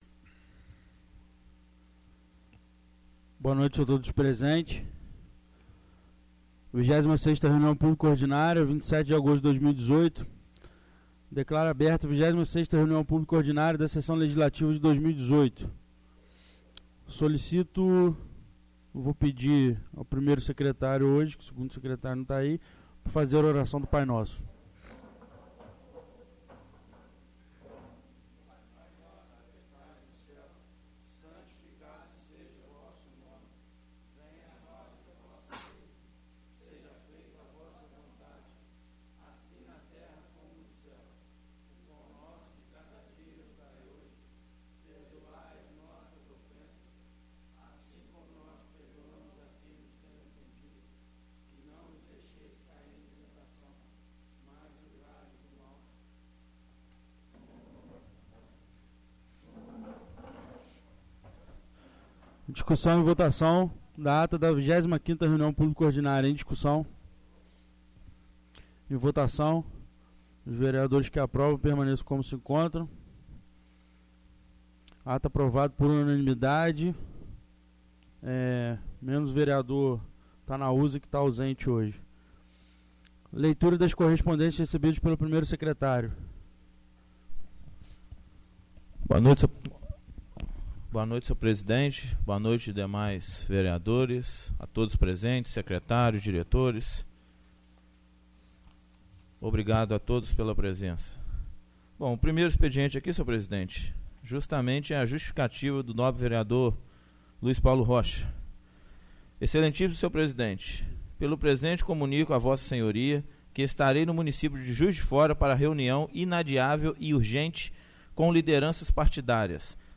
26º Reunião Pública Ordinária 27/08/2018